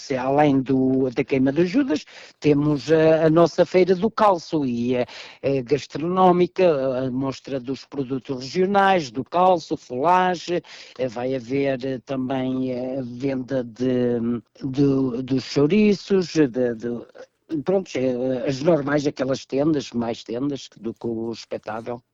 Para além do certame, será possível adquirir produtos regionais na mostra de venda de produtos locais, onde o calço assume papel de destaque, acrescenta o autarca: